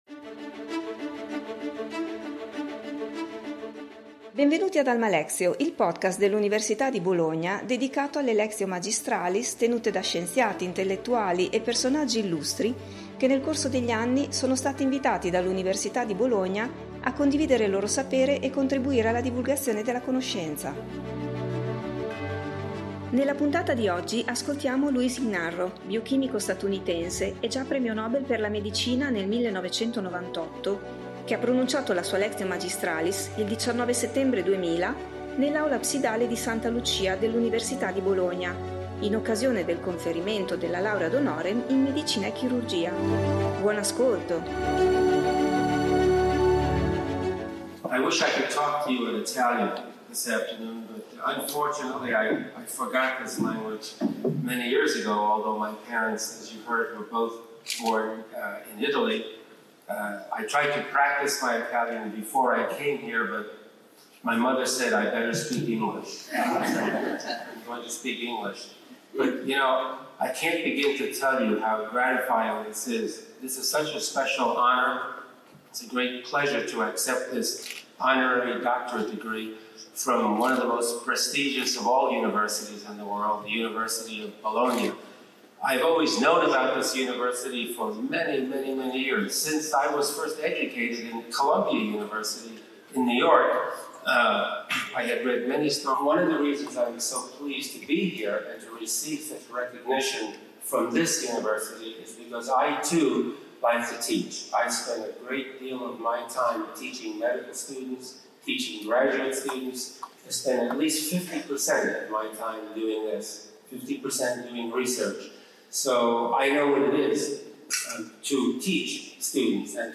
Louis Ignarro, biochimico statunitense e già premio Nobel per la medicina nel 1998 per avere scoperto le implicazioni della molecola di monossido di azoto nel sistema cardiovascolare, ha pronunciato la sua lectio magistralis il 19 settembre 2000 nell’Aula Absidale di Santa Lucia dell’Università di Bologna in occasione del conferimento della Laurea honoris causa in medicina e chirurgia.